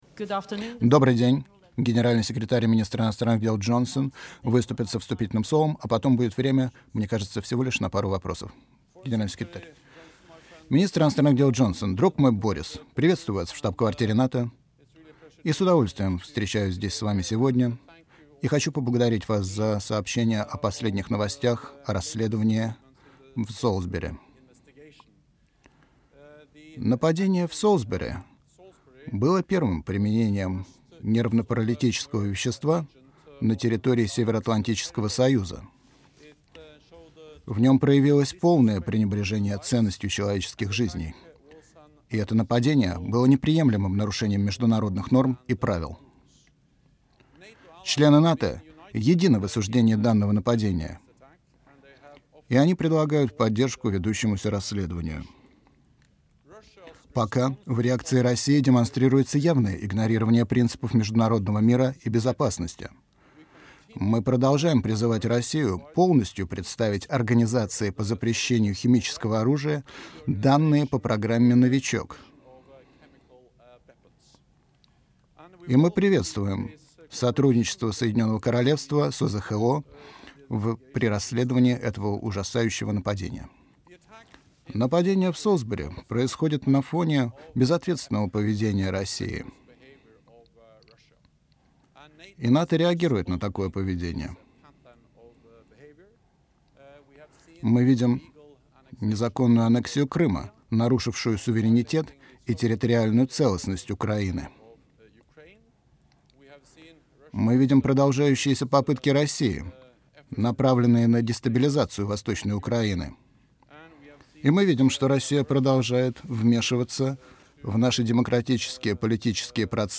Joint press point
with NATO Secretary General Jens Stoltenberg and UK Foreign Secretary Boris Johnson